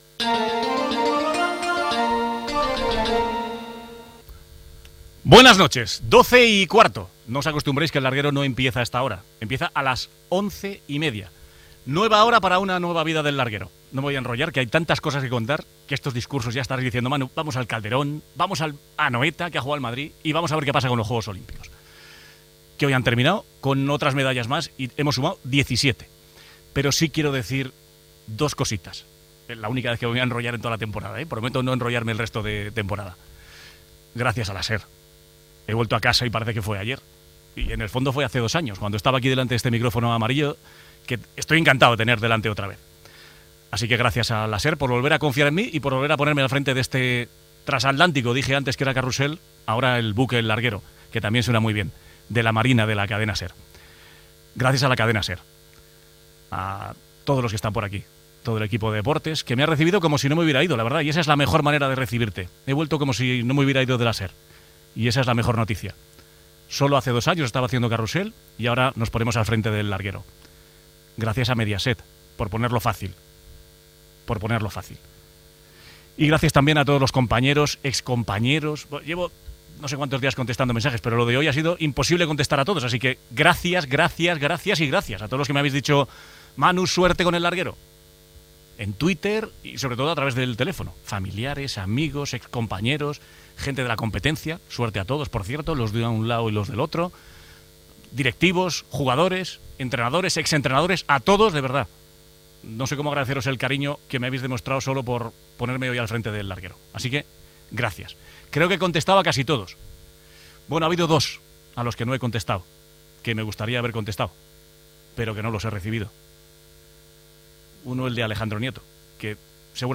Hora, primera edició del programa presentada per Manu Carreño. Salutació, agraïments, nova sintonia del programa
Esportiu